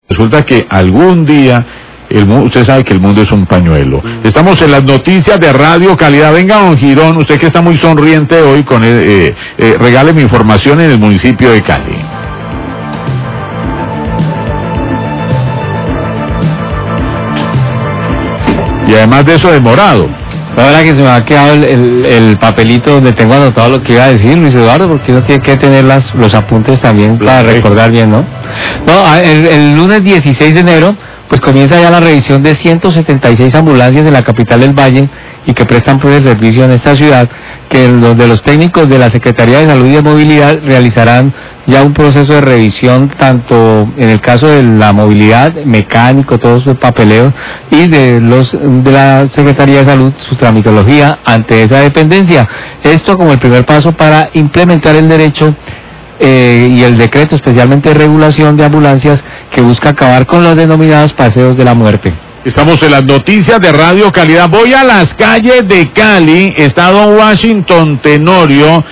NOTICIAS DE CALIDAD